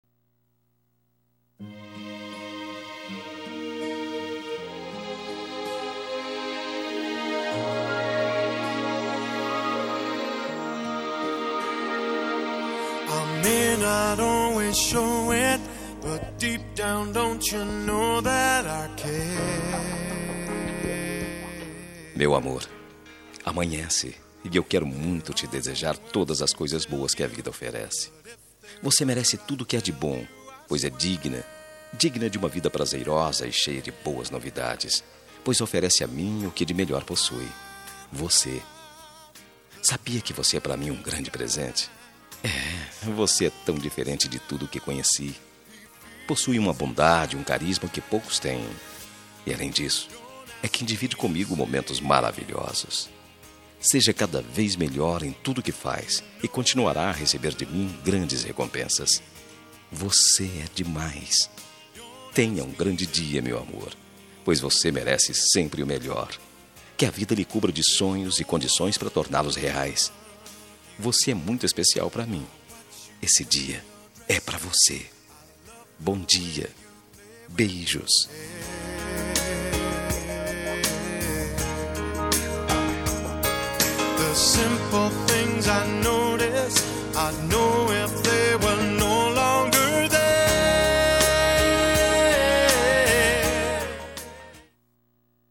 Telemensagem de Bom dia – Voz Masculina – Cód: 6328 – Romântica